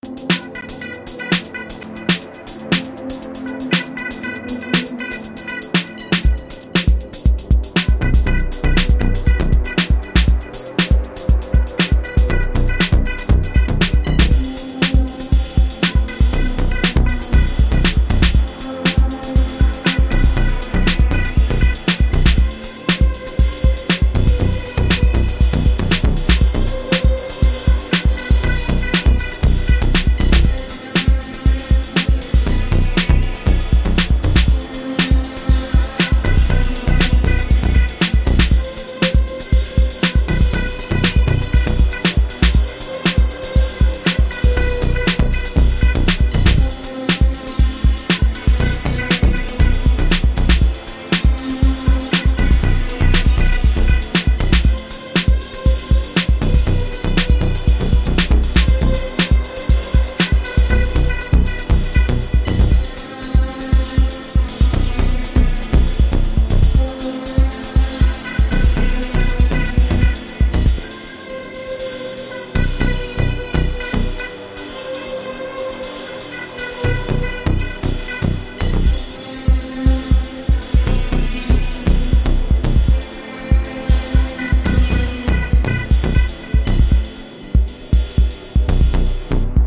electro
resonant